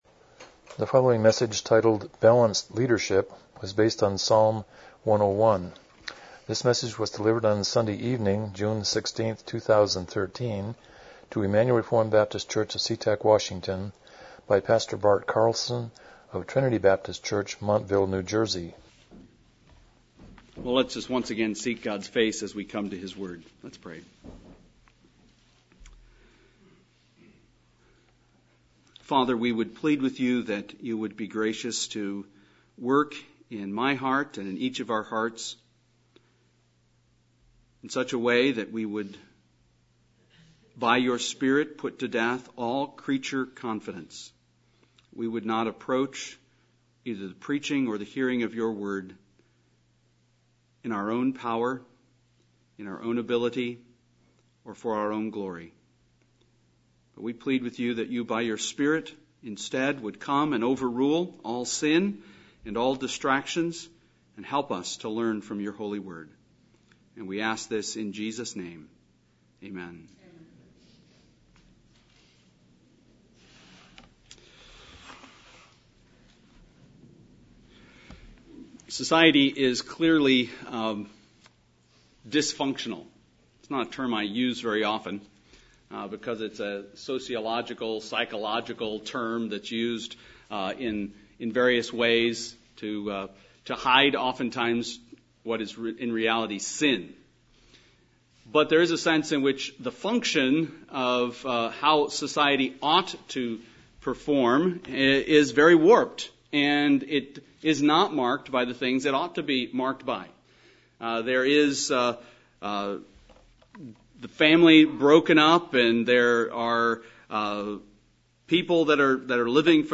Psalm 101:1-8 Service Type: Evening Worship « 3 Avoiding Worldliness